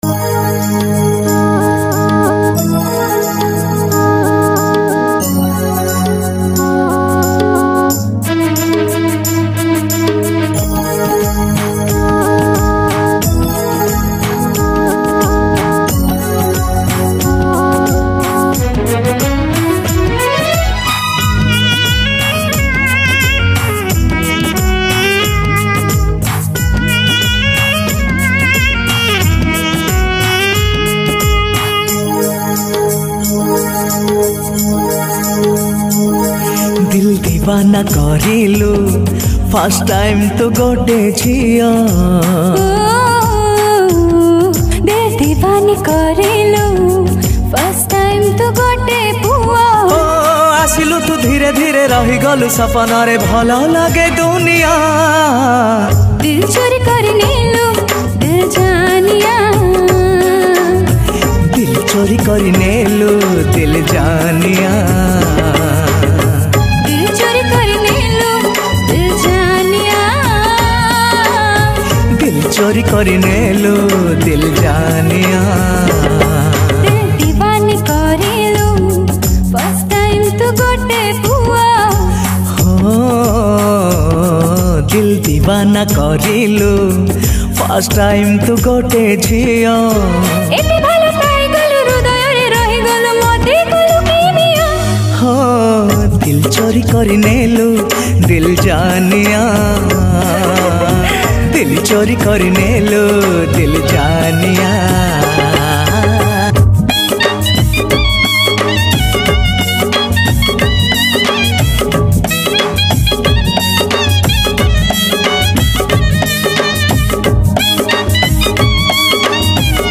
Odia Romantic Song
New Odia Album Songs